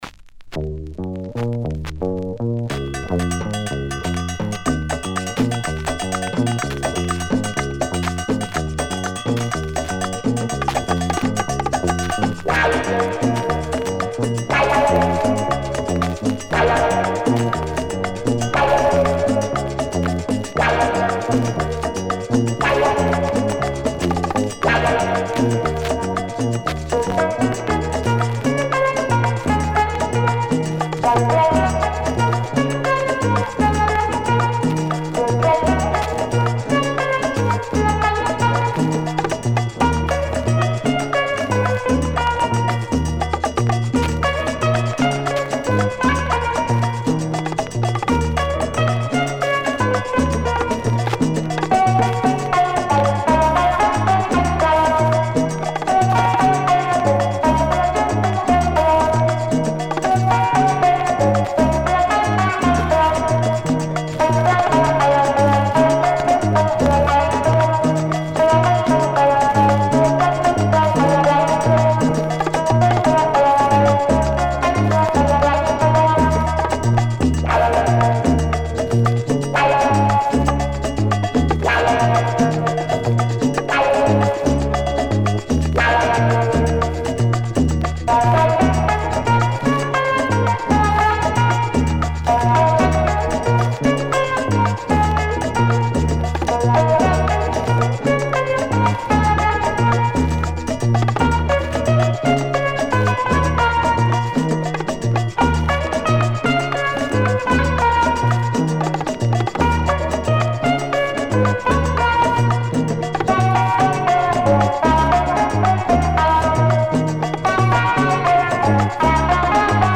Published February 13, 2010 Cumbia , Garage/Rock Comments